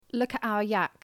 /j/ yacht versus / dʒ / jot